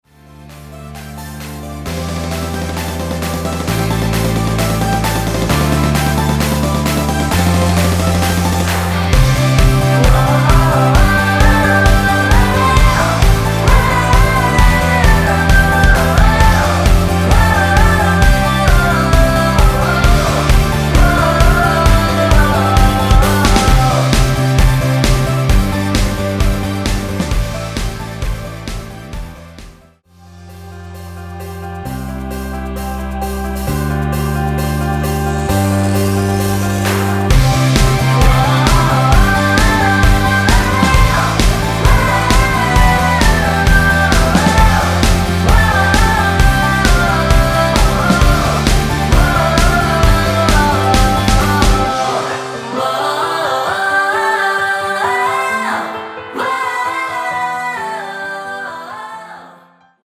원키에서(+3)올린 코러스 포함된 MR입니다.(미리듣기 확인)
앞부분30초, 뒷부분30초씩 편집해서 올려 드리고 있습니다.